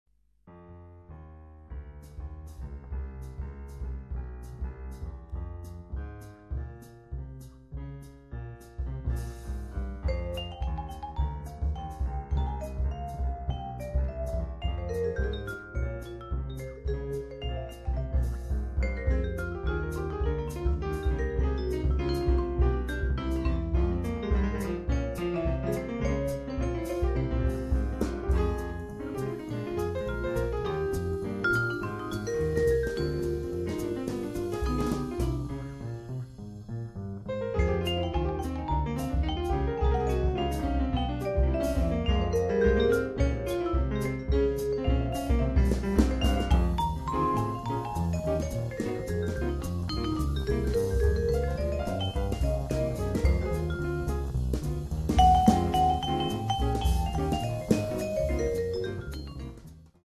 vibrafono
pianoforte
contrabbasso
batteria